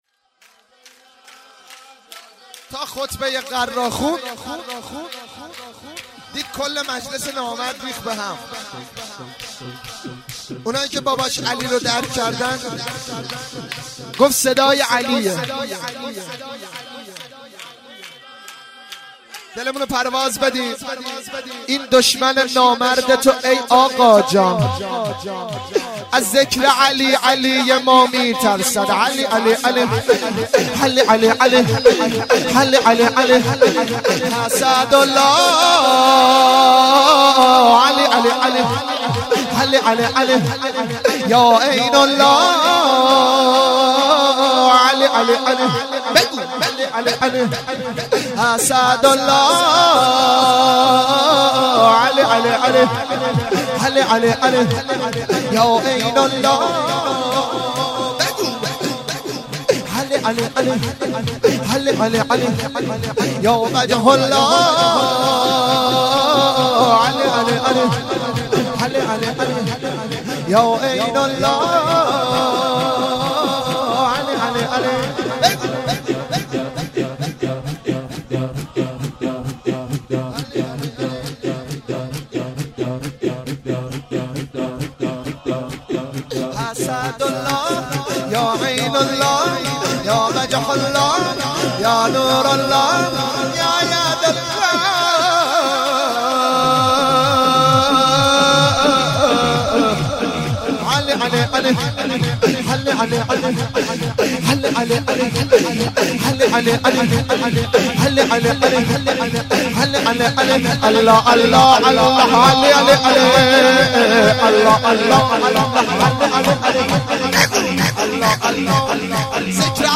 شور
جشن ولادت حضرت زینب(س)